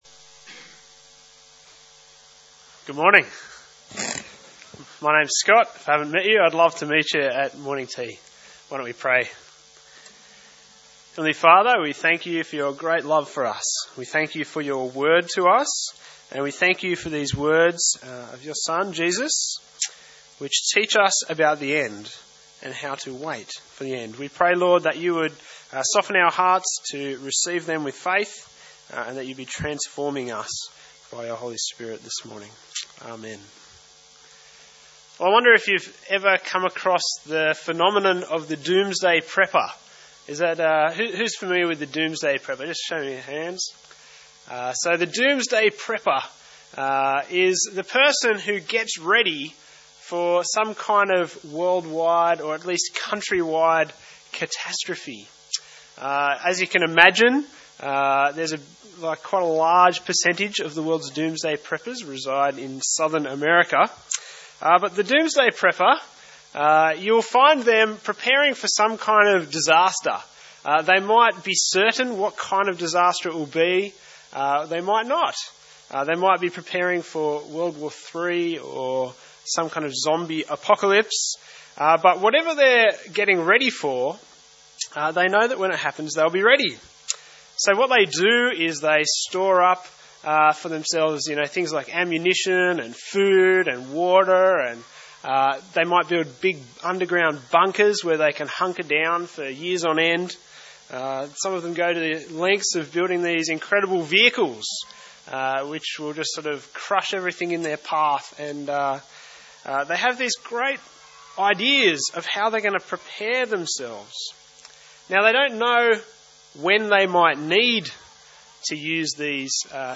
View Sermon details and listen